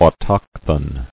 [au·toch·thon]